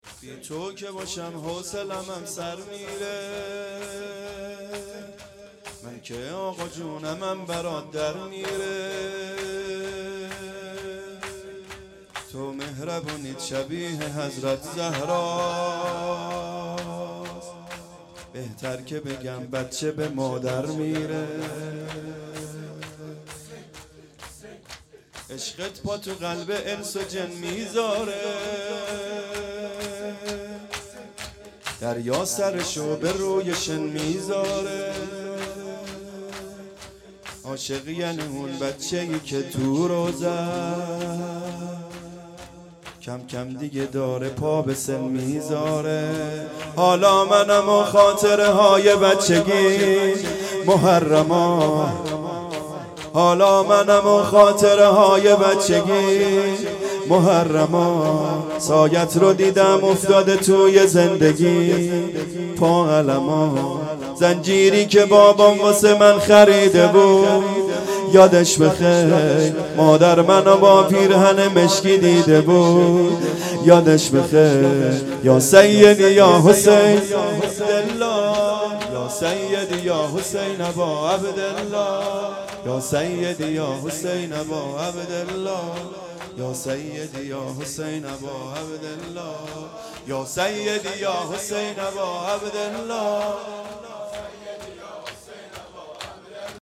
بی تو که باشم حوصلمم سر میره _ شور
محرم 1440 _ شب نهم